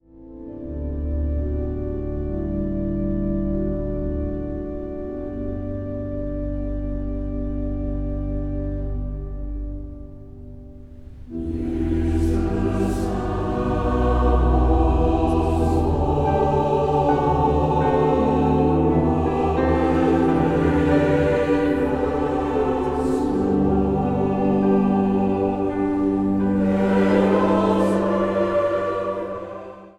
orgel
vleugel
koorsolisten.
Zang | Gemengd koor